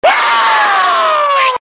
Ichabod’s Scream Sound Effect Free Download
Ichabod’s Scream